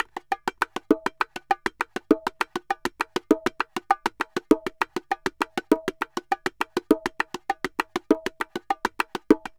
Bongo_Salsa 100_1.wav